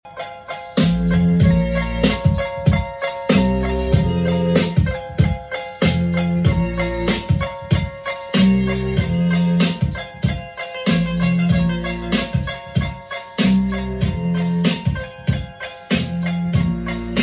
✨ Philips Philharmonic Radiogram Restored sound effects free download